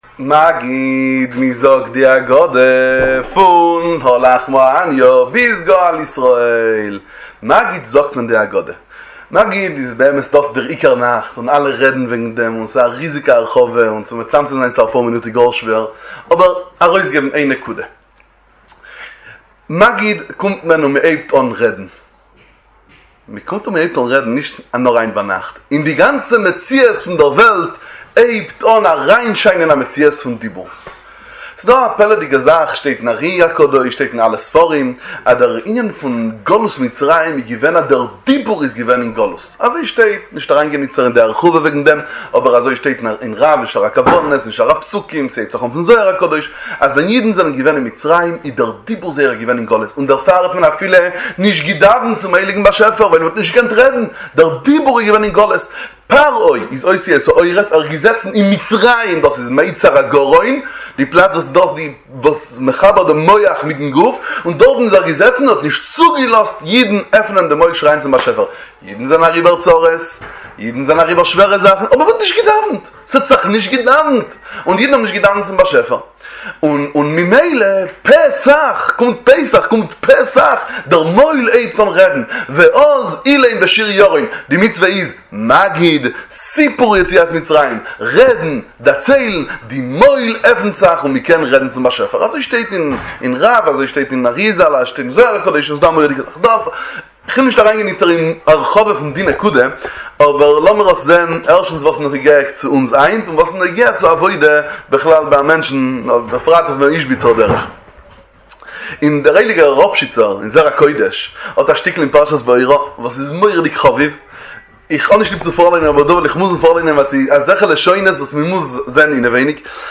דבר תורה קצר לליל הסדר ולחג הפסח באידיש, סדרת שיעורי תורה ליל הסדר בתורת איזביצה